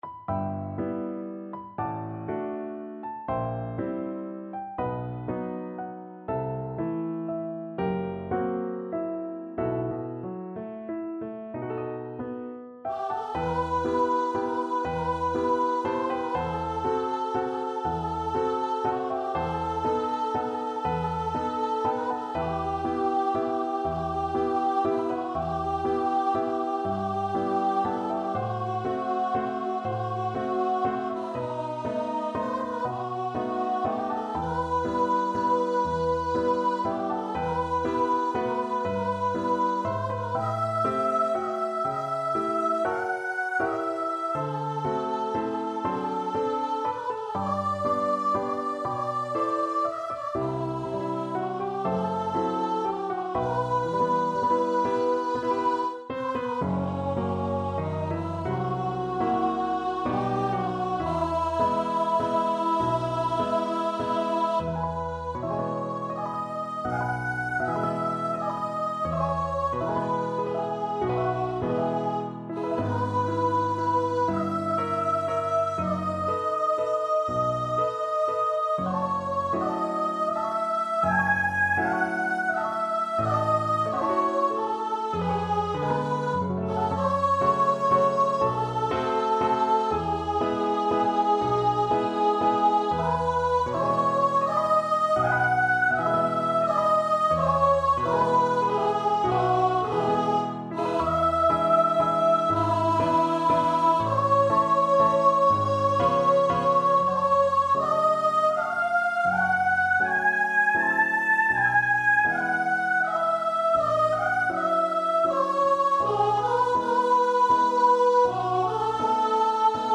3/4 (View more 3/4 Music)
~ = 120 Lento
B4-A6
Classical (View more Classical Voice Music)